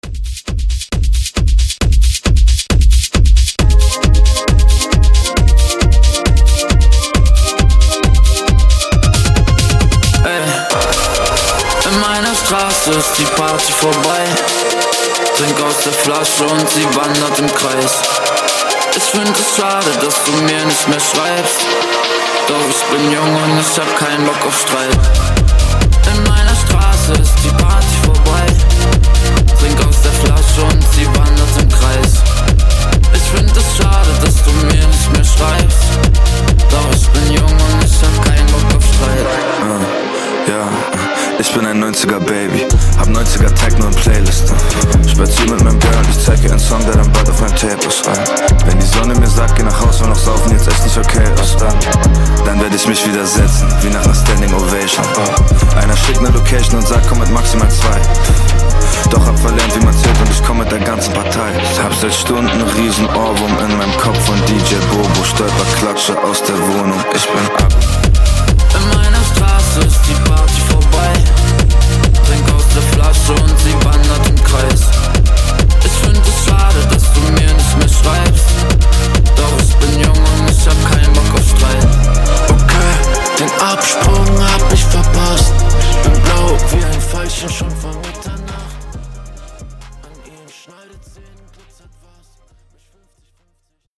BPM: 135 Time